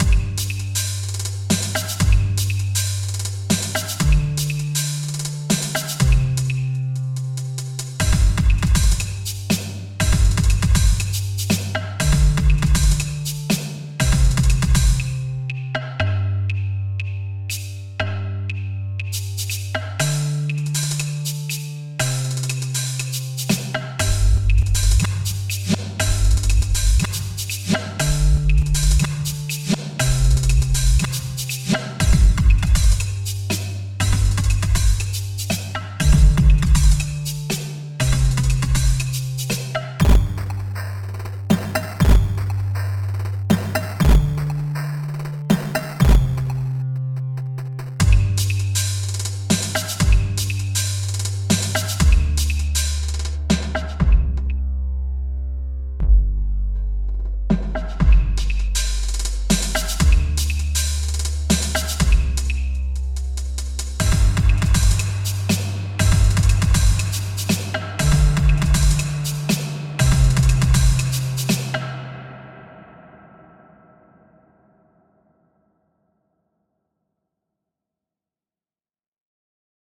Layer diverse percussion sounds, sculpt them with precision using the EQ, and explore creative possibilities with dual filter and effect channels.
• Drumboy - Drum Machine
drumboy-record.mp3